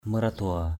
/mə-ra-d̪ʊa:/ maradua mrd&% [A, 381] (mara < dua) (t.) hai lòng, mưu mẹo, xảo trá, xảo quyệt, tráo trở = partagé en deux, fourbe, rusé, artificieux; duplicité, mauvaise foi= satified, cunning, crafty...